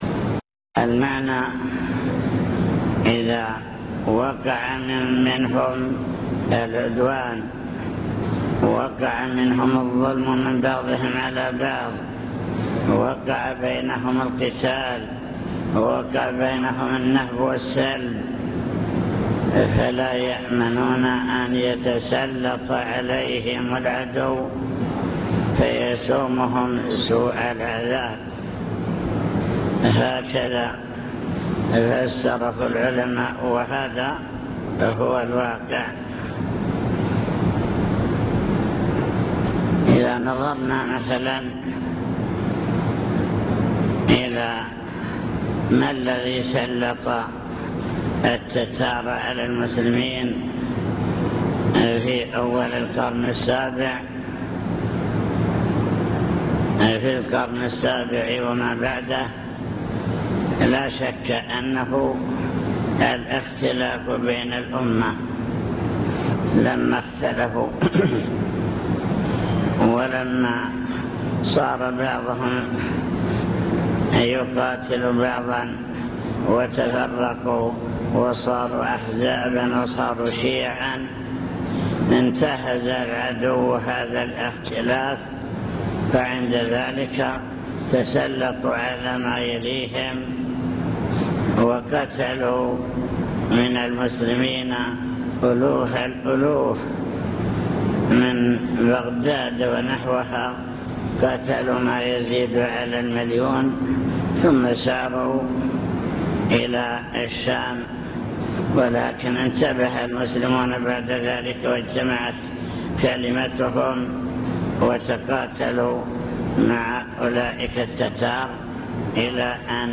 المكتبة الصوتية  تسجيلات - لقاءات  كلمة في مسجد نعم الله لا تحصى